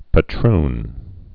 (pə-trn)